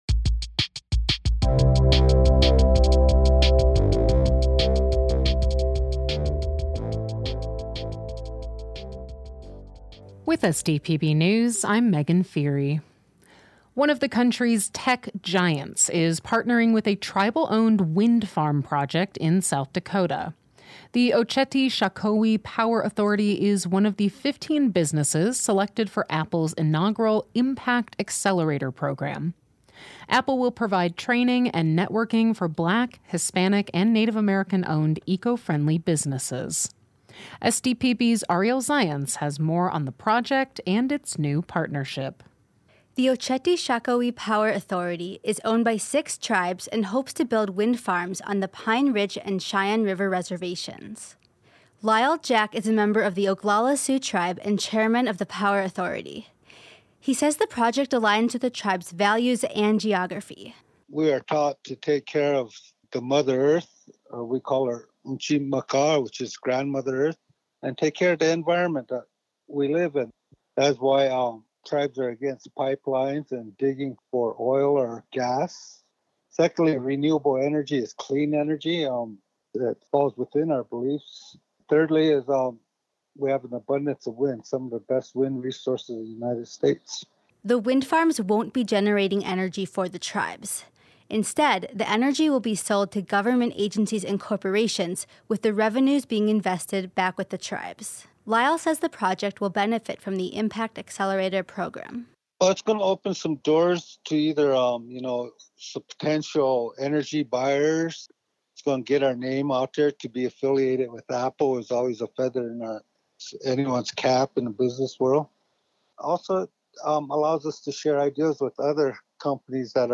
We then compile those stories into one neatly formatted daily podcast so that you can stay informed.